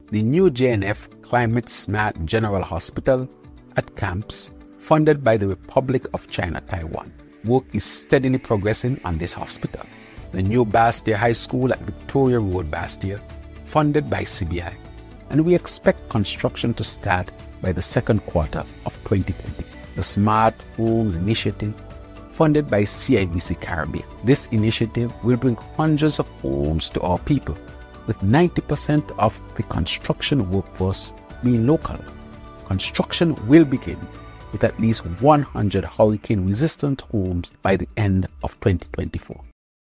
It is the Federal Government’s desire that the “transformative projects” will change SKN’s dependency on CBI to SEED (Sustainable Economic Expansion and Diversification Development) pillars, which comes under the umbrella of SISA (Sustainable Island State Agenda). In an address on Oct. 17th, PM Drew spoke of the projects: